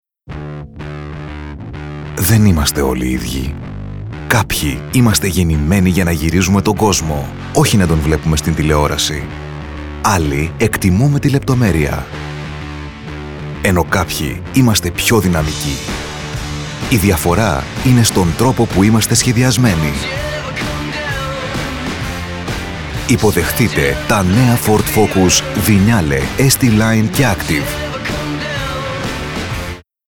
Fast, polished delivery in native Greek or English.
Automotive
BassDeepLow
WarmCharming